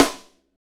Index of /90_sSampleCDs/Northstar - Drumscapes Roland/SNR_Snares 1/SNR_Funk Snaresx